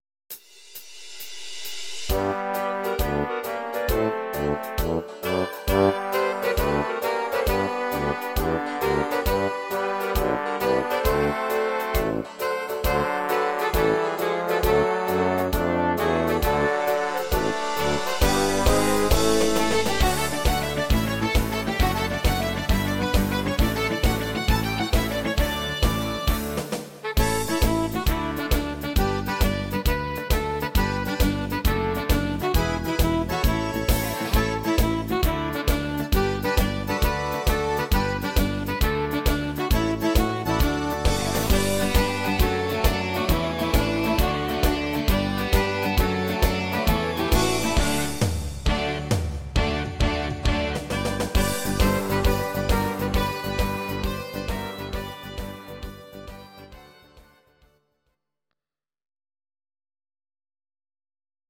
Please note: no vocals and no karaoke included.